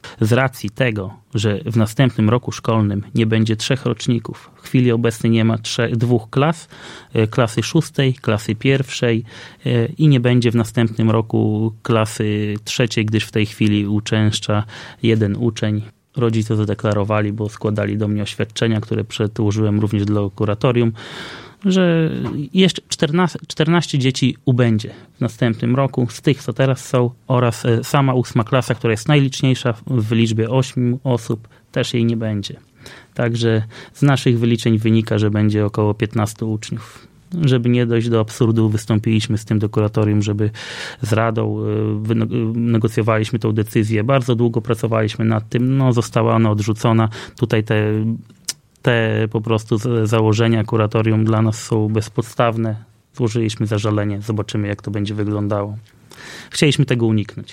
Samorząd chciał zmniejszyć liczbę klas i utworzyć w Karolinie filię szkoły w Gibach, bo w przyszłym roku szkolnym uczniów ma być jeszcze mniej, a dokładnie piętnastu.  – Nie chcieliśmy dojść do absurdu – tłumaczy wójt.